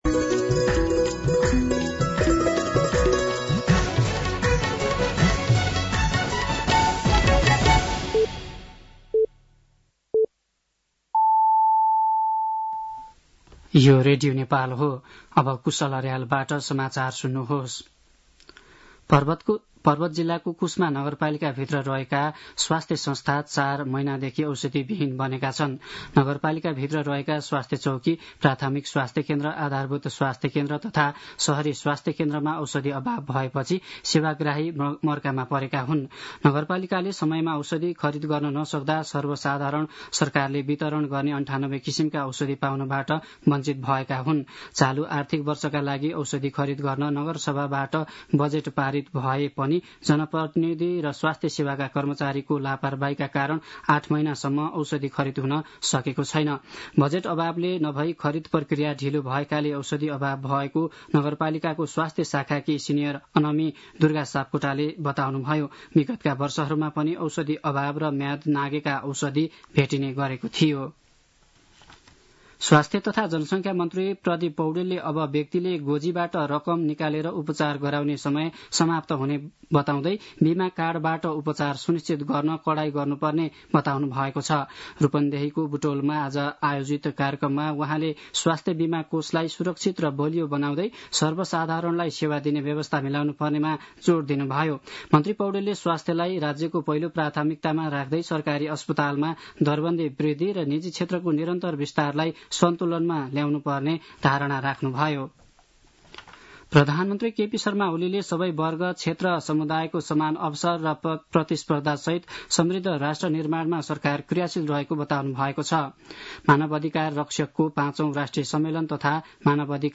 साँझ ५ बजेको नेपाली समाचार : २२ फागुन , २०८१
5-pm-news-2.mp3